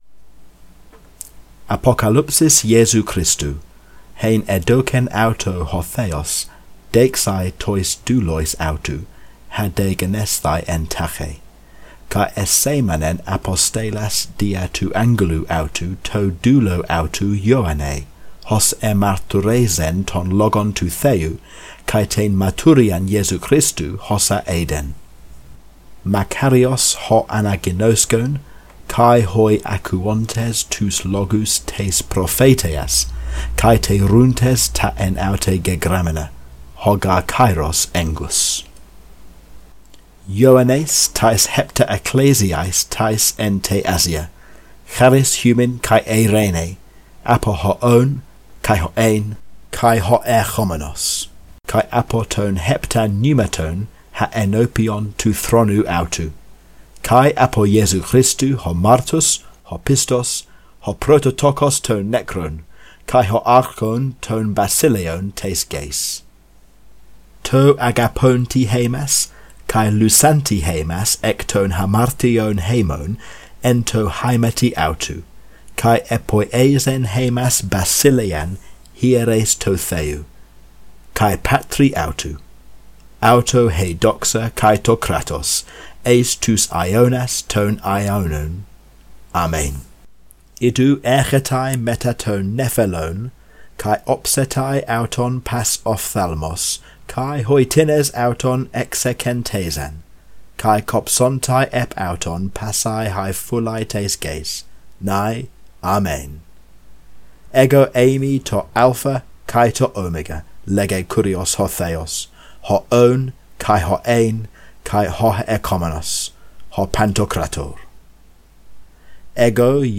Be warned: My pronunciation will be (very) flawed. My fluency is still developing. And as for accent, let's not even go there.